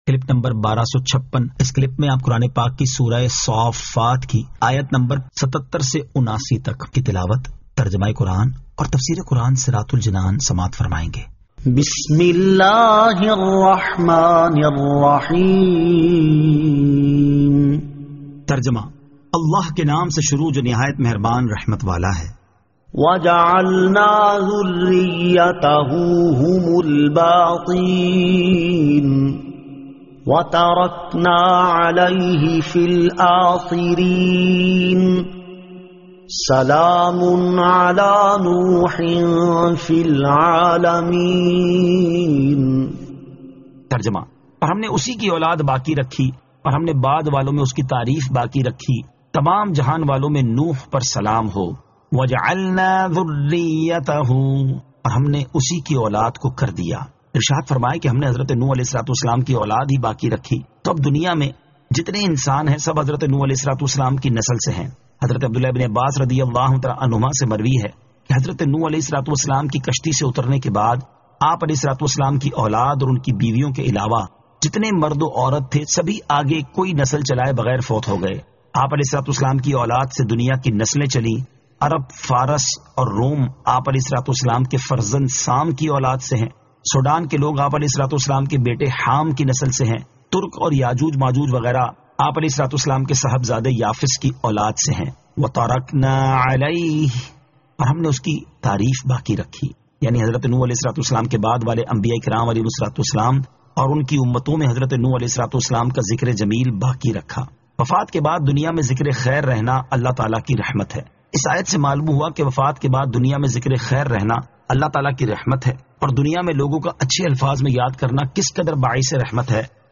Surah As-Saaffat 77 To 79 Tilawat , Tarjama , Tafseer